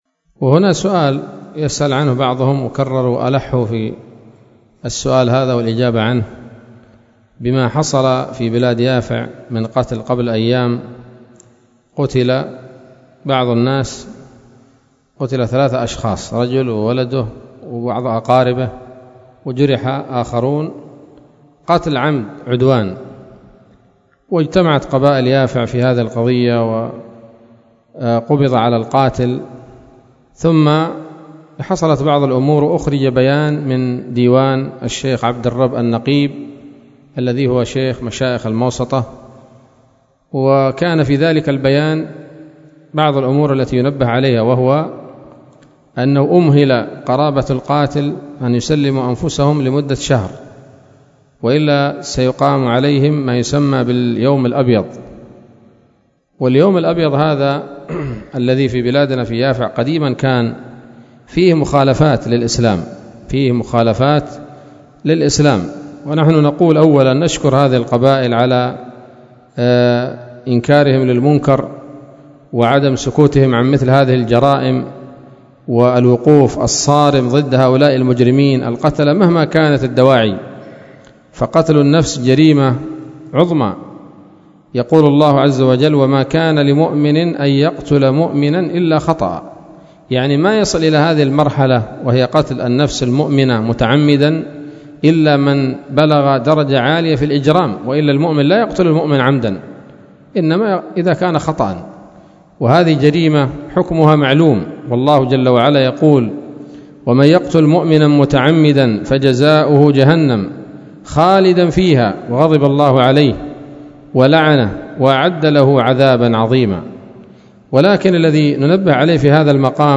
ليلة الجمعة 25 ذي القعدة 1443هـ، بدار الحديث السلفية بصلاح الدين